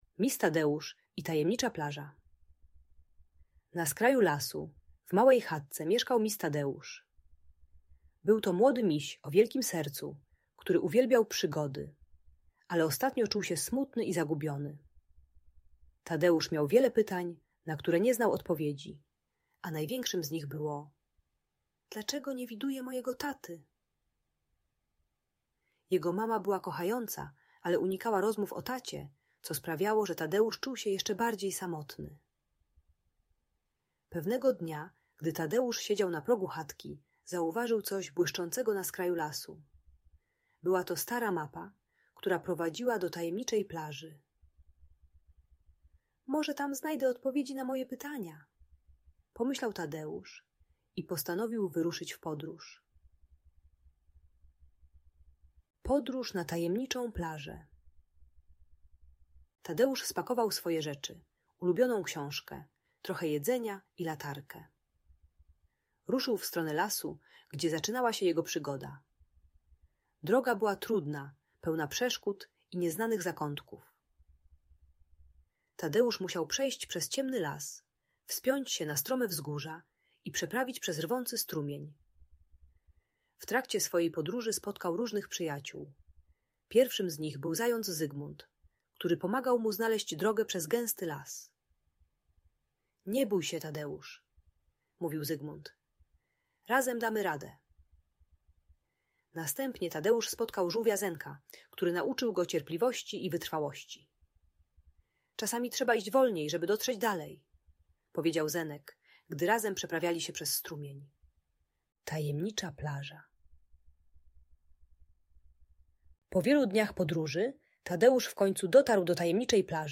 Historia Misia Tadeusza - Odkryj Tajemniczą Plażę - Audiobajka